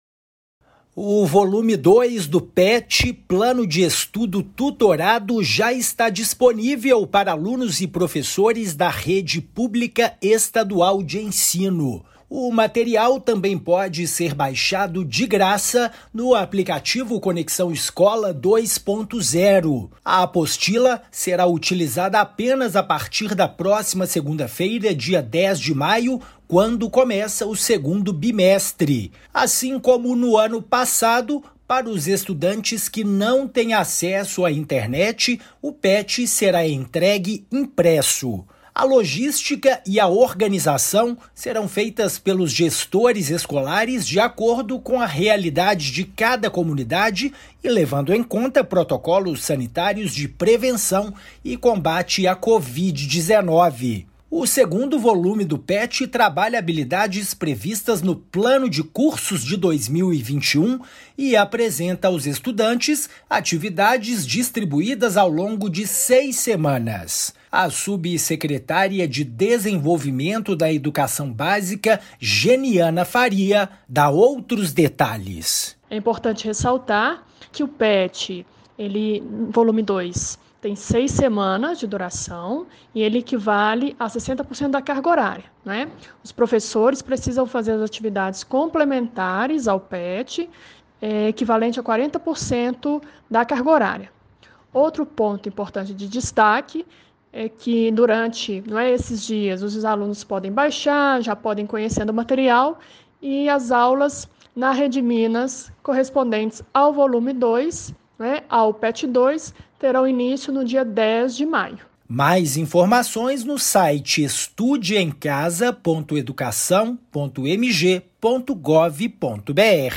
Material pode ser baixado gratuitamente no site Estude em Casa e no aplicativo Conexão Escola 2.0. Ouça a matéria de rádio.